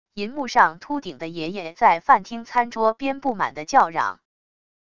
银幕上秃顶的爷爷在饭厅餐桌边不满地叫嚷wav音频